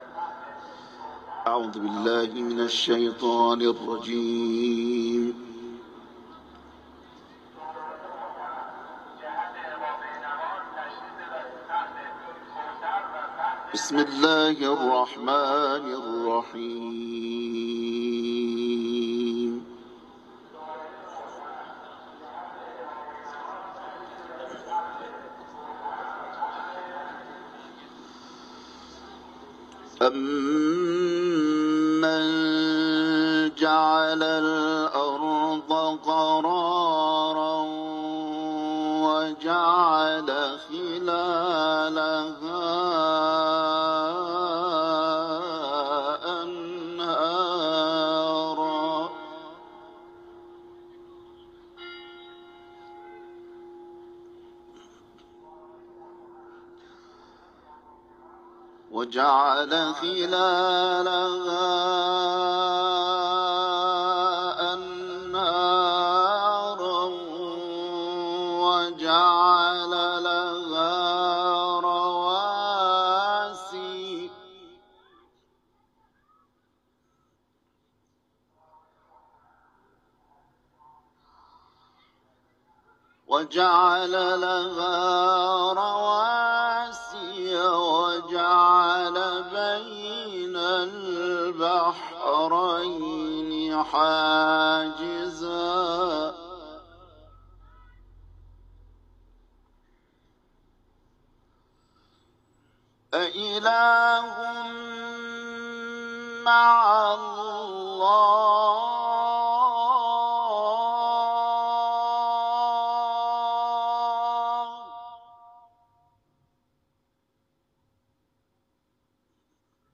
تلاوت
حرم مطهر رضوی